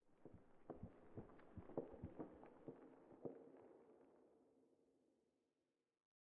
pale_hanging_moss12.ogg